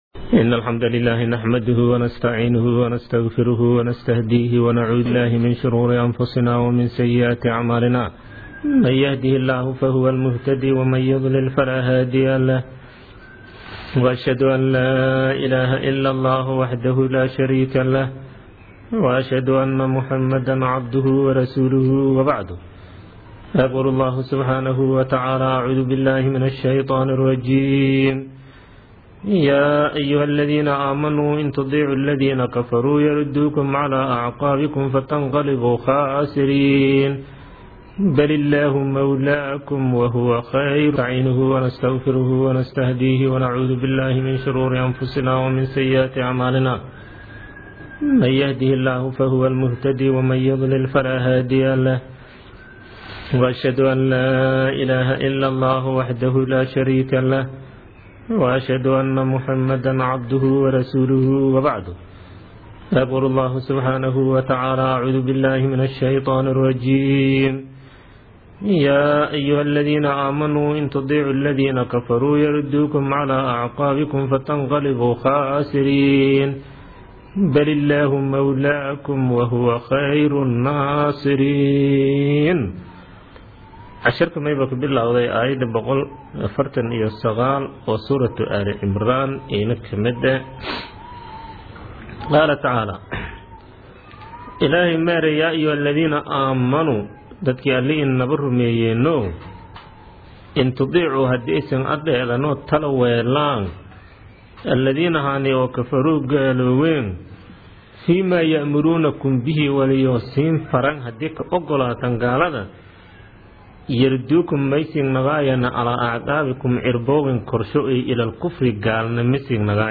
Casharka Tafsiirka Maay 53aad